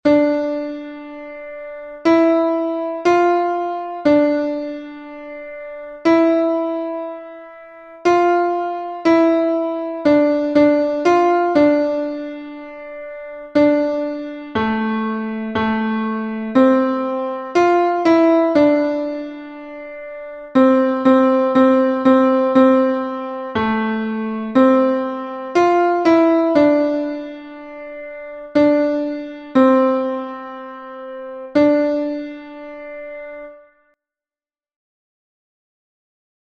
Cantando gregoriano
Aquí tes unha partitura de inspiración gregoriana coa letra coa que acabas de traballar.